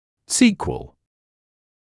[‘siːkwəl][‘сиːкуэл]результат, последствие; продолжение